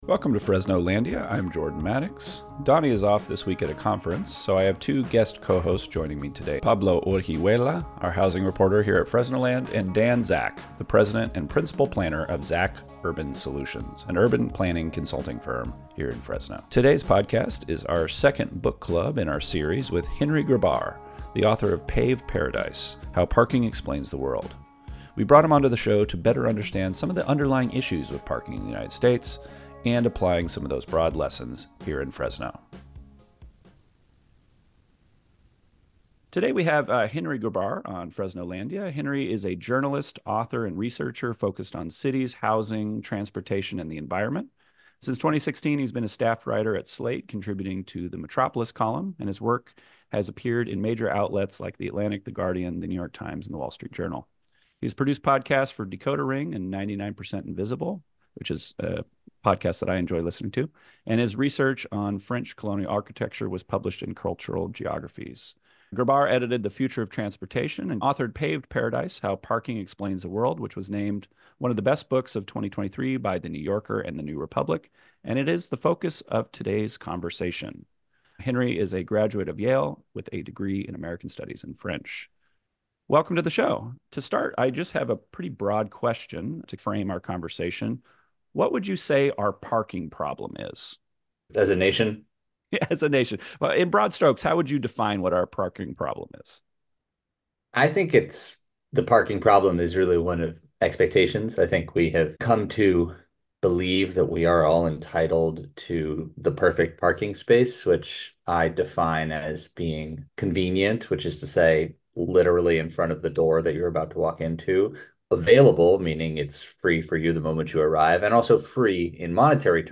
We discuss some of the key concepts in his book, discuss how these ideas can be applied here in Frenso, and take community questions from our listeners.&nbsp